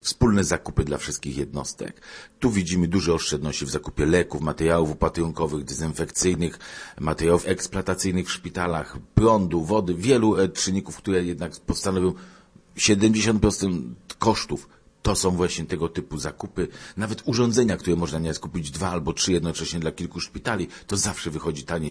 Zajmie się między innymi budową nowego szpitala dziecięcego i nadzorem budowlano-inwestycyjnym nad lecznicami, które podlegają urzędowi marszałkowskiemu - mówił w porannym programie Radia Merkury "Z jakiej racji" Leszek Wojtasiak, wicemarszałek województwa. Spółka ma też koordynować zakupy dla 25 marszałkowskich szpitali i przychodni.